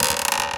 chair_frame_metal_creak_squeak_14.wav